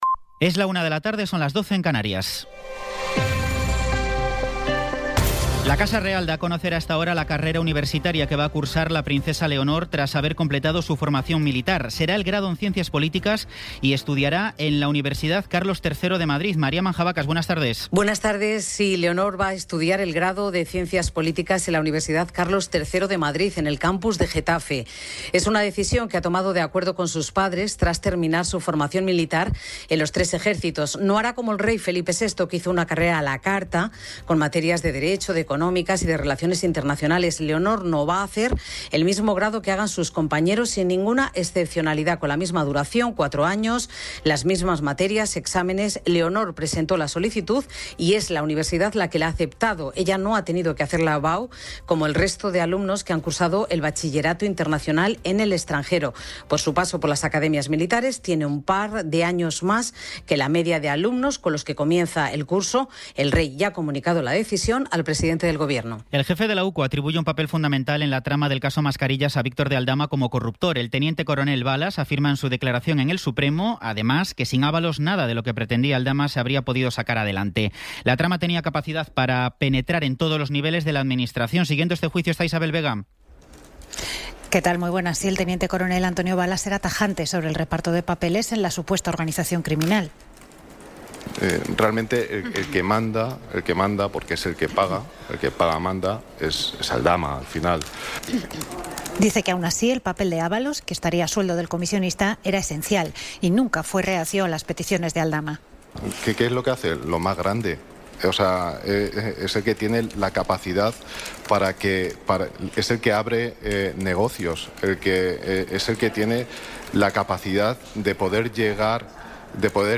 Resumen informativo con las noticias más destacadas del 27 de abril de 2026 a la una de la tarde.